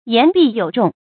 言必有中 yán bì yǒu zhòng 成语解释 中：关键的地方。
成语简拼 ybyz 成语注音 ㄧㄢˊ ㄅㄧˋ ㄧㄡˇ ㄓㄨㄙˋ 常用程度 常用成语 感情色彩 褒义成语 成语用法 主谓式；作分句；含褒义，形容说话中肯 成语结构 主谓式成语 产生年代 古代成语 成语正音 中，不能读作“zhōnɡ”。